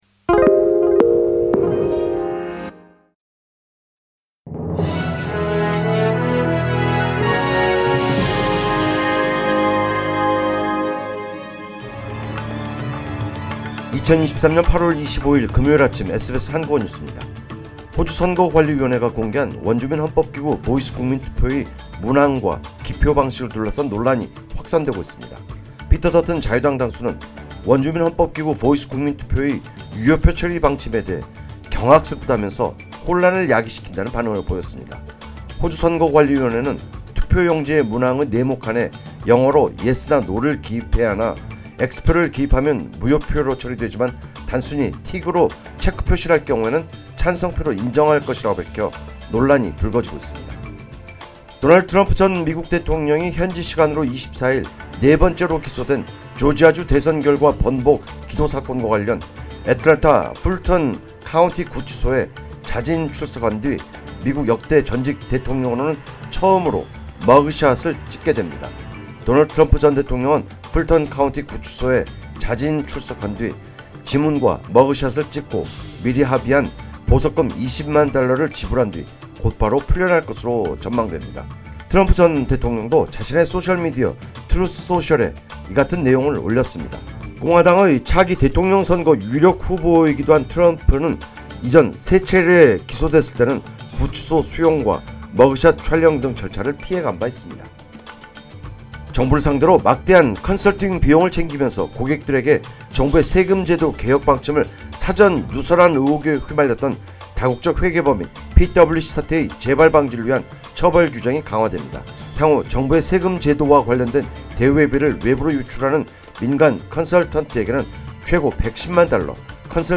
2023년 8월 25일 금요일 아침 SBS 한국어 뉴스